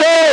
DDW2 CHANT 2.wav